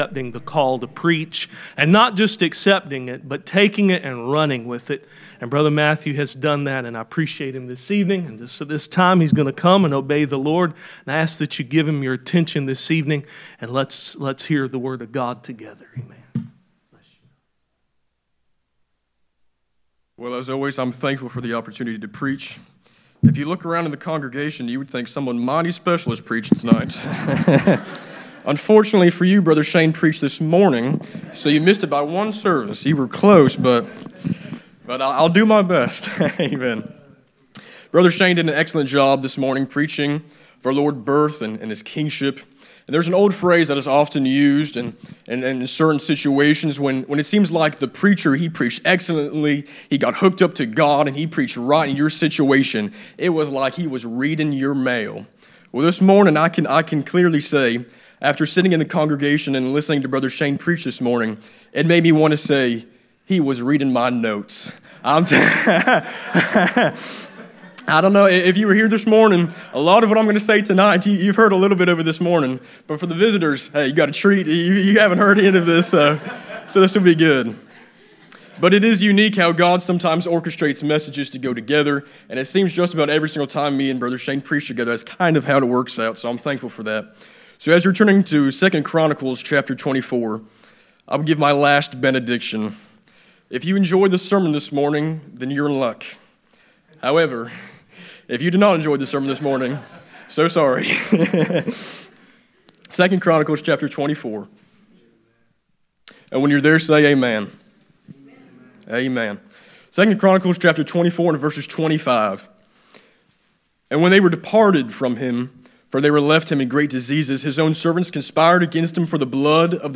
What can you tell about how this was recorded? None Passage: 2 Chronicles 24:25-25:2 Service Type: Sunday Evening %todo_render% « Is there no King Conceived by the Holy Ghost